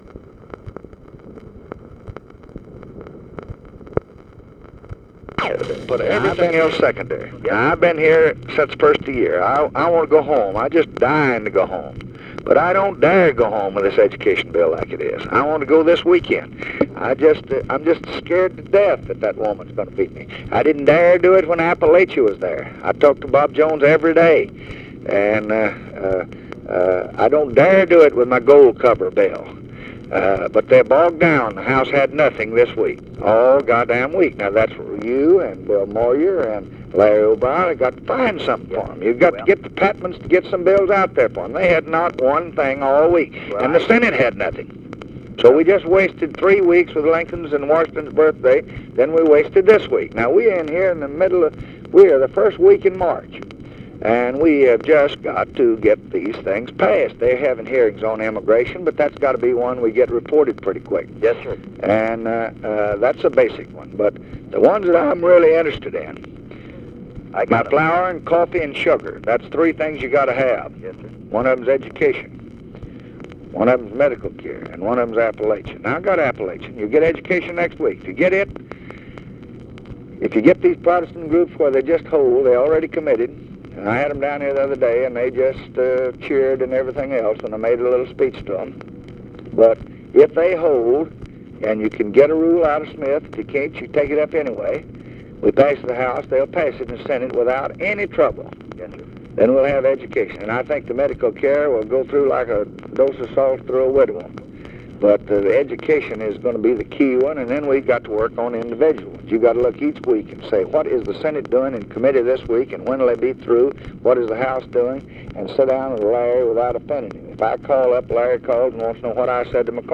Conversation with HUBERT HUMPHREY, March 6, 1965
Secret White House Tapes